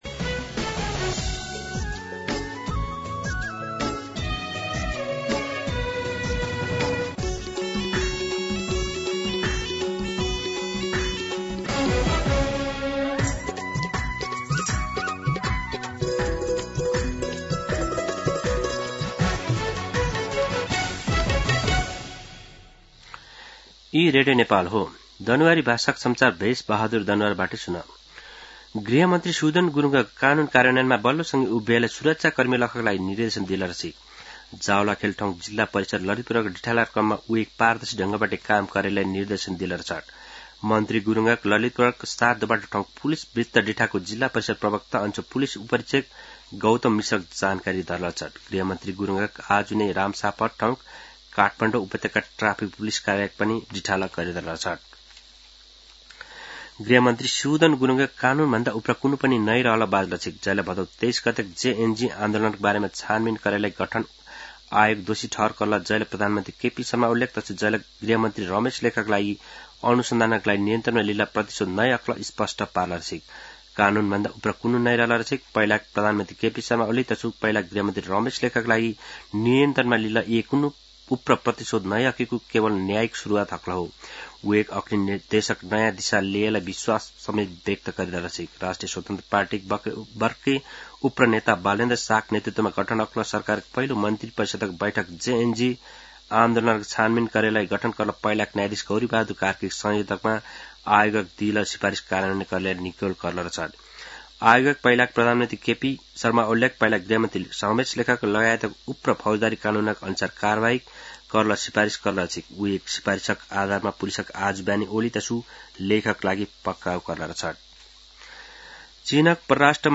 दनुवार भाषामा समाचार : १४ चैत , २०८२
Danuwar-News-14.mp3